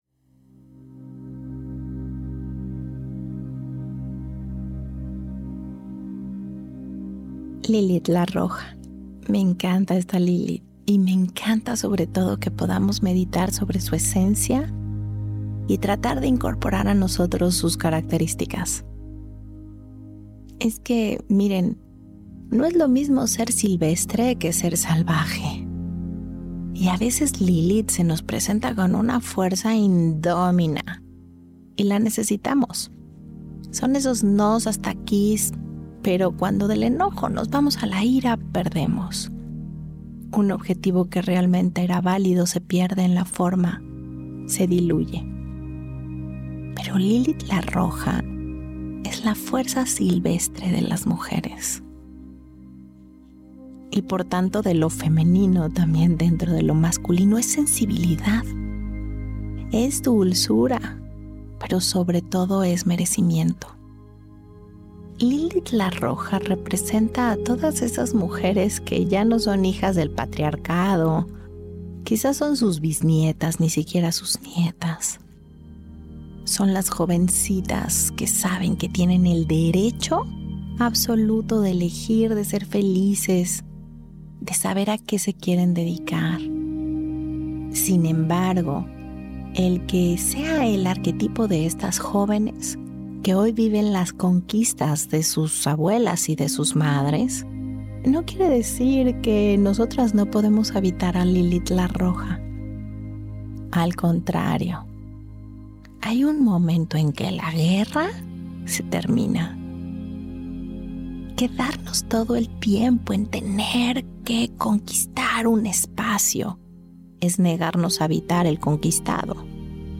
Meditaciones Lilith la Roja, la otra cara de la inocencia Esta Lilith nos conecta con ese lado de nosotras que no conoce ataduras.